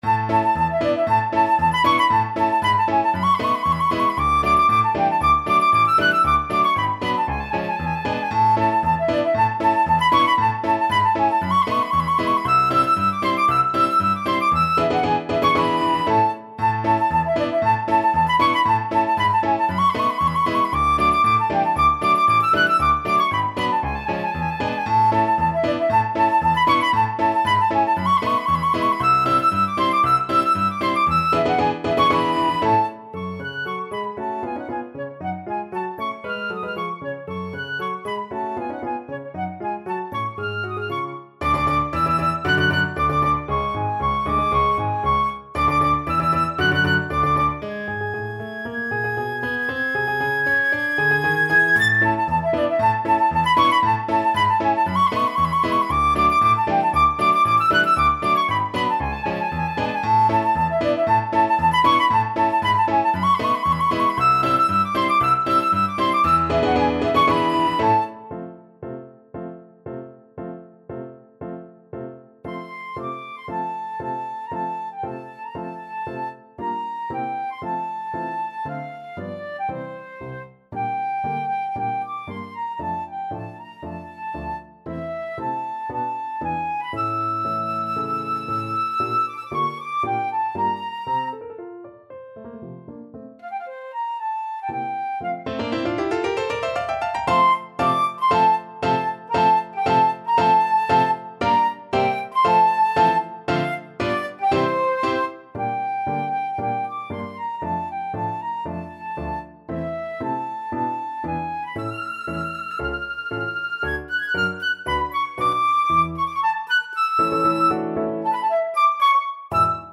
Flute
2/4 (View more 2/4 Music)
C6-A7
A major (Sounding Pitch) (View more A major Music for Flute )
Allegro giocoso =116 (View more music marked Allegro giocoso)
Classical (View more Classical Flute Music)